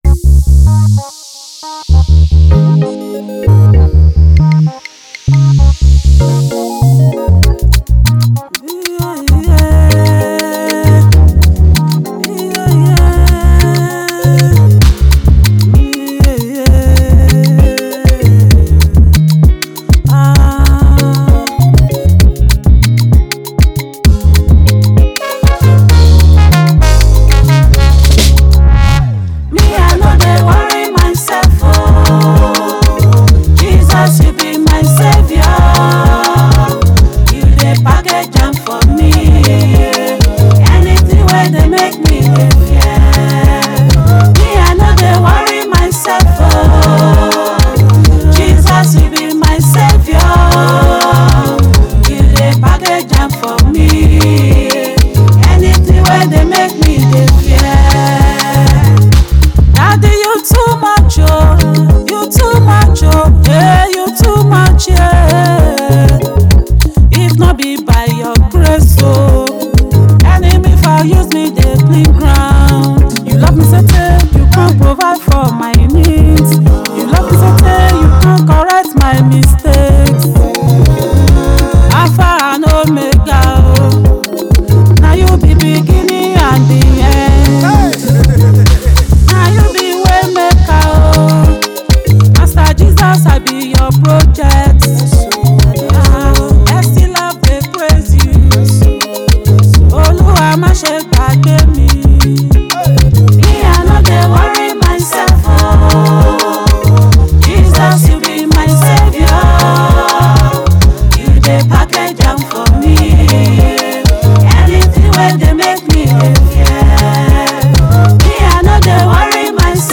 Popular Gospel singer